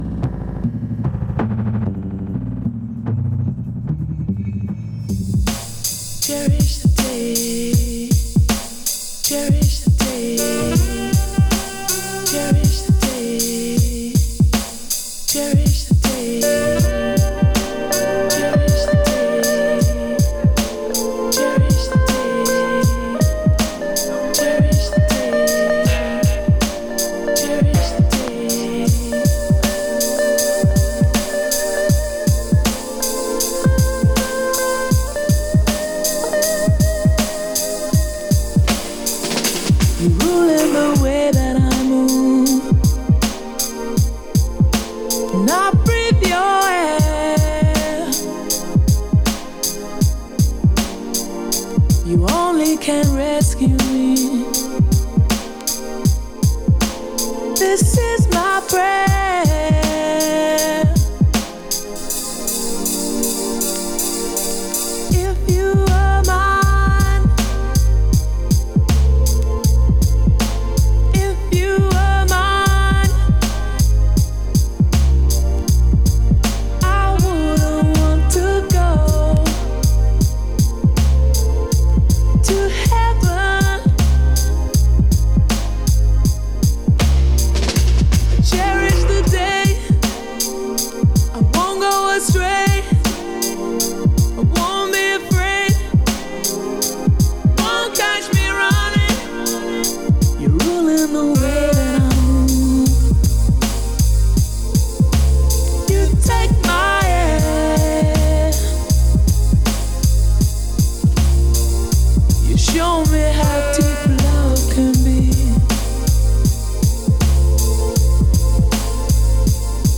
Dance Disco Downtempo Soul